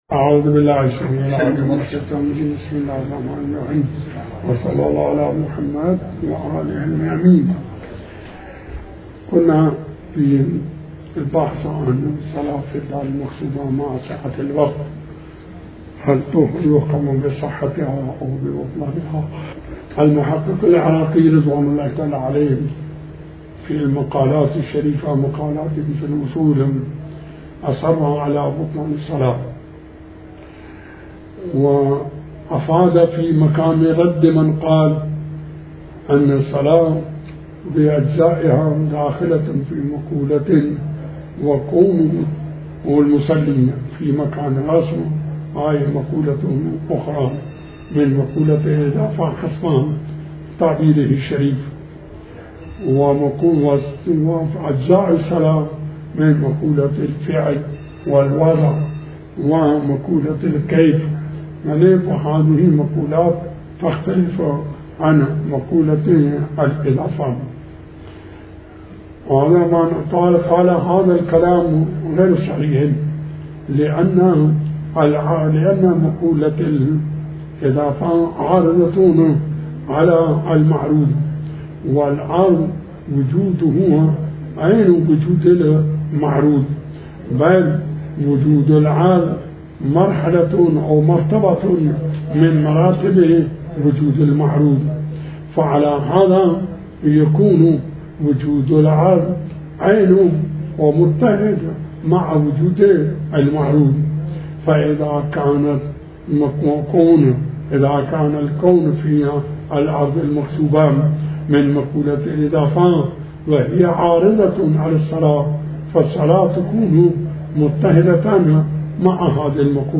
تحمیل آیةالله الشيخ بشير النجفي بحث الفقه 38/03/20 بسم الله الرحمن الرحيم الموضوع : التيمم _ شرائط التيمم _ مسألة 10) : الخاتم حائل فيجب نزعه حال التيمم.